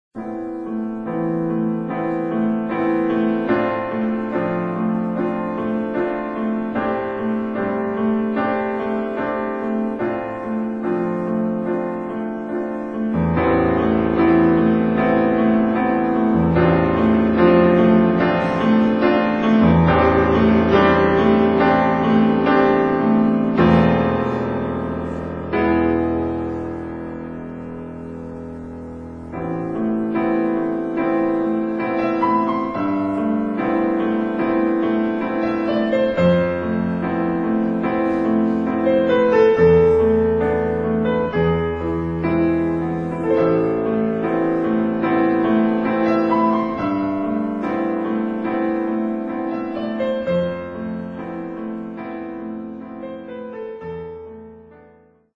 ライブならではの緊張感と臨場感をお楽しみください。